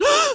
Искренний неподдельный испуг женщины
iskrennij_nepoddelnij_ispug_zhenshini_bwk.mp3